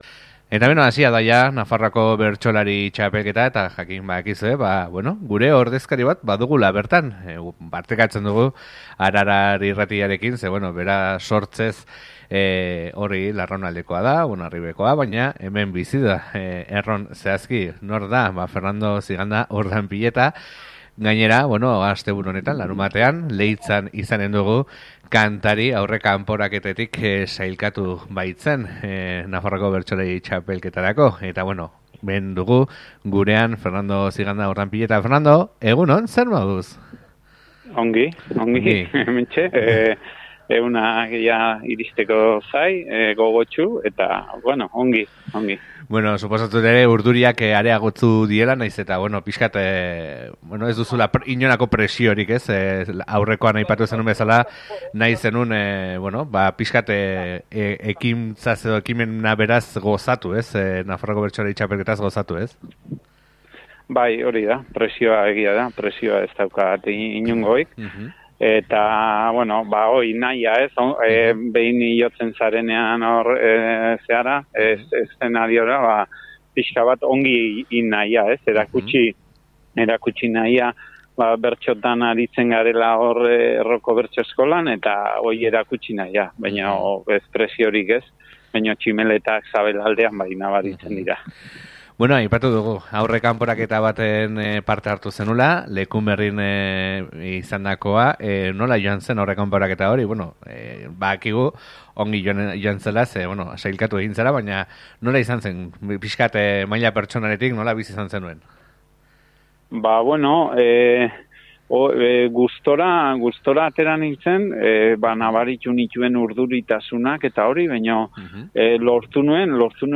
Arduraz ikusten du bere parte-hartzea baina gozatzeko esperantzarekin, presiorik ez baitio bere buruari jarri nahi saio on bat egitea besterik. Gaur berarekin izan gara honetaz solasean.